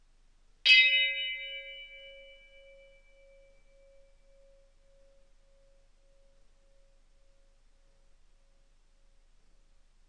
Bell 07
bell bing brass ding sound effect free sound royalty free Sound Effects